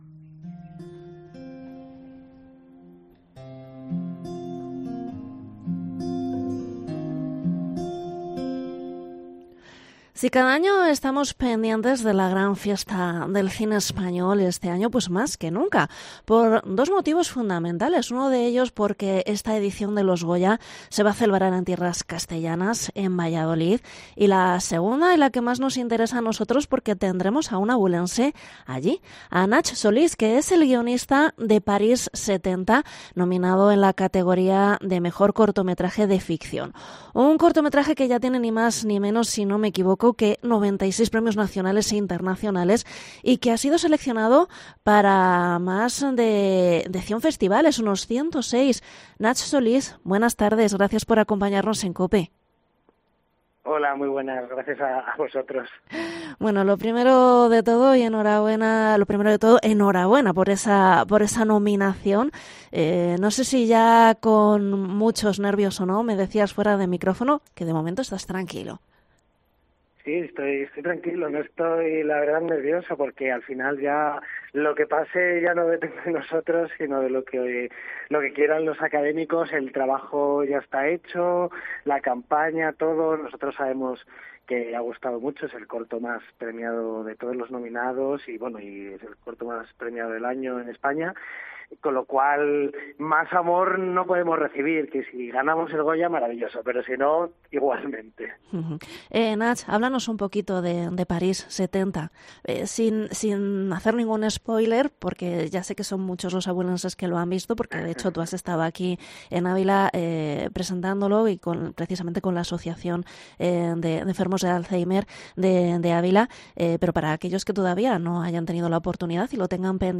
ENTREVISTA al guionista abulense